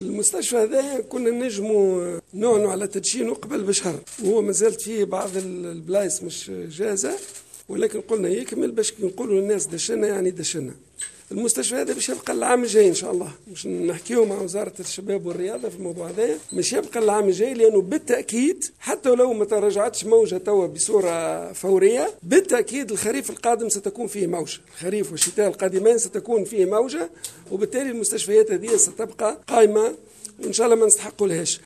أكّد وزير الصحة عبد اللطيف المكي اليوم الاربعاء 13 ماي 2020، في تصريح لإذاعة موزاييك أف أم أن الخريف والشتاء القادمان سيشهدان موجة ثانية من انتشار عدوى فيروس كورونا المستجد.